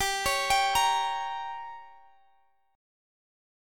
Listen to Gdim strummed